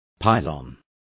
Also find out how pilon is pronounced correctly.